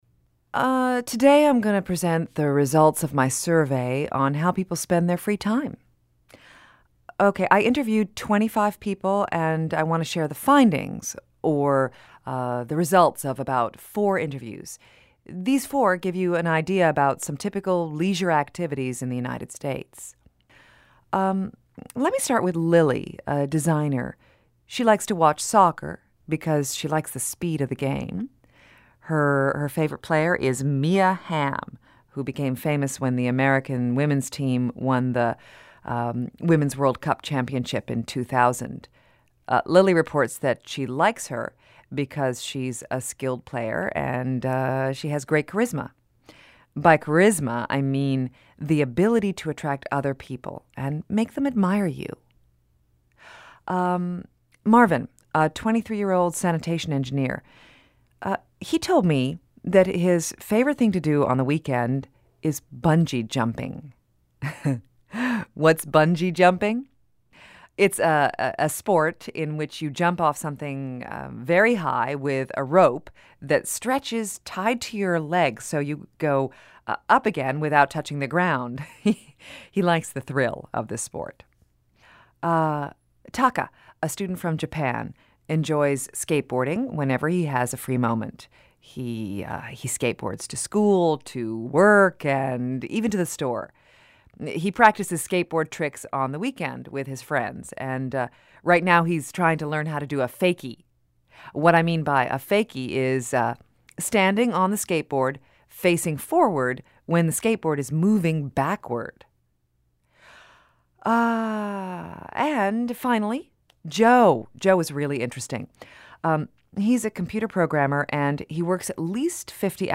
Lecture - pages 44. Do page 45